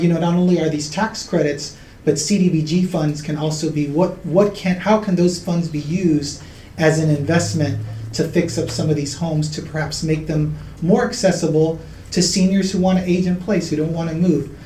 during Tuesday’s Mayor and City Council meeting